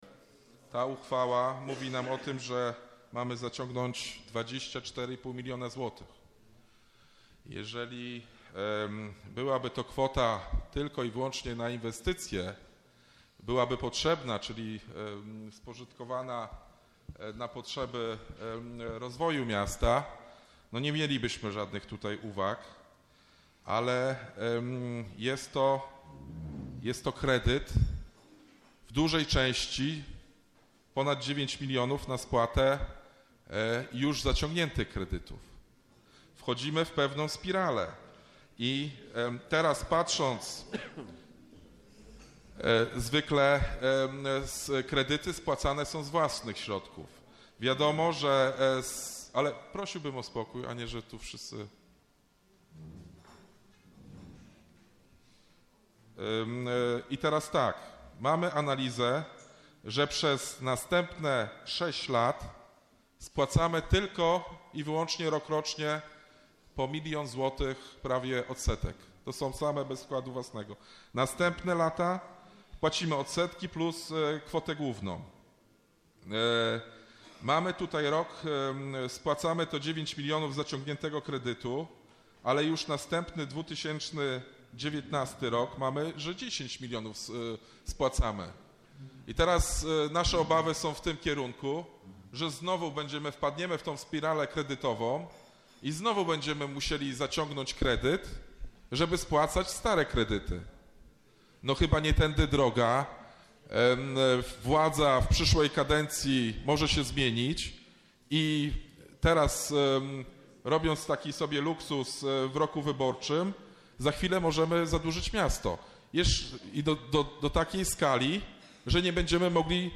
Bardzo zaskakujący przebieg miała wczorajsza sesja Rady Miasta.